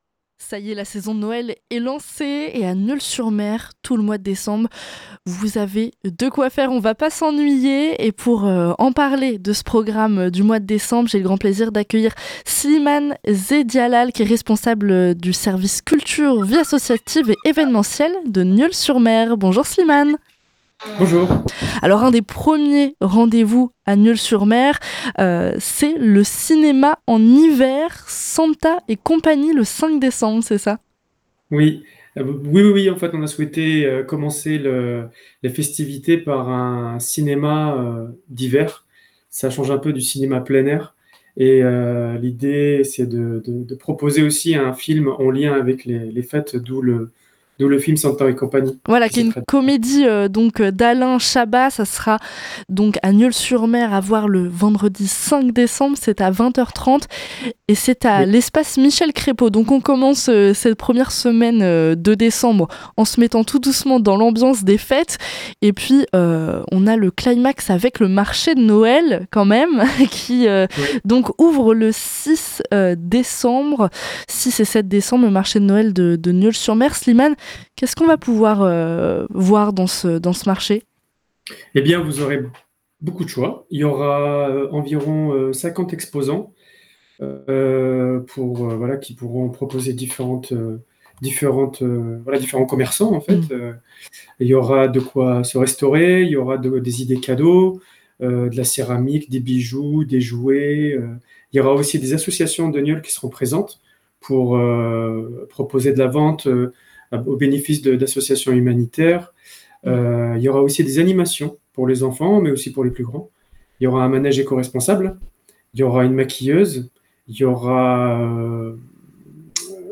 L’interview est à retrouver ci-dessous.